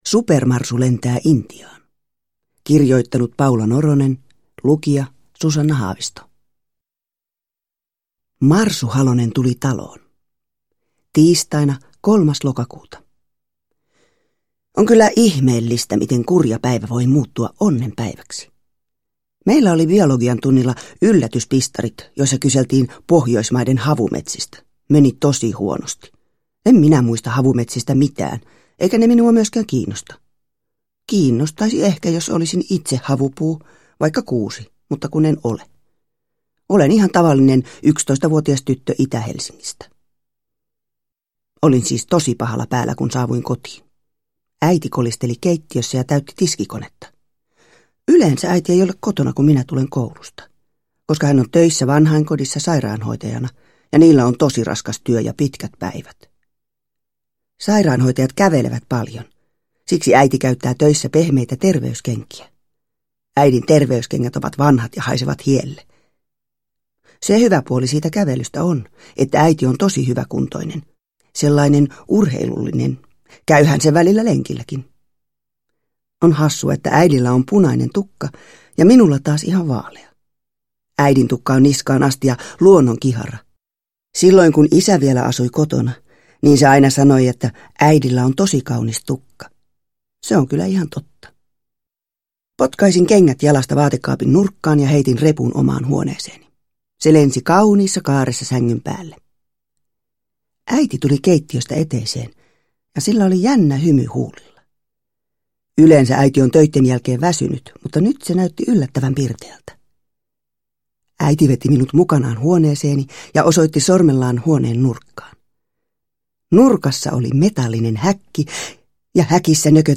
Supermarsu lentää Intiaan – Ljudbok
Uppläsare: Susanna Haavisto